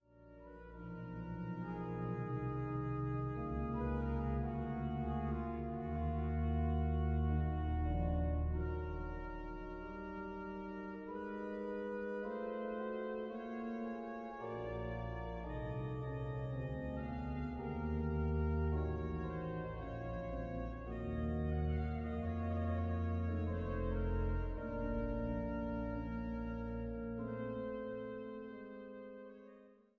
Woehl-Orgel in der Thomaskirche zu Leipzig